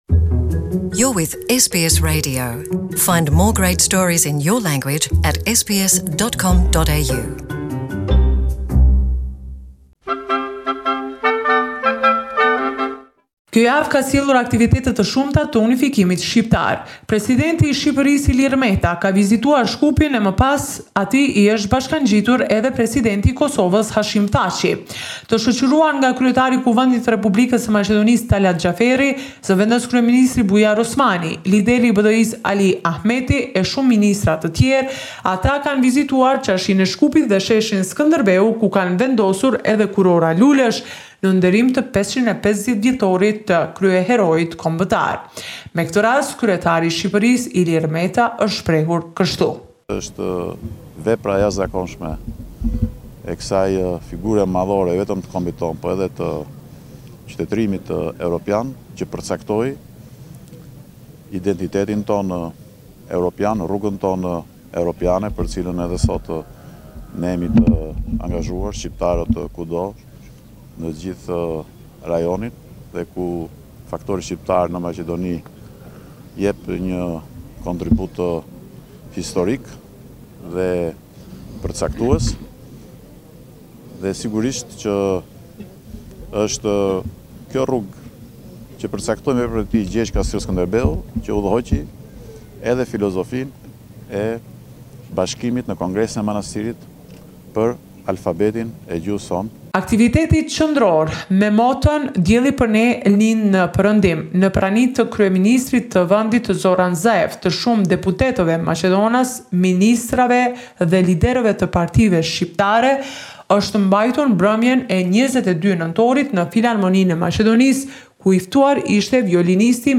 This is a report summarising the latest developments in news and current affairs in Macedonia